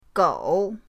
gou3.mp3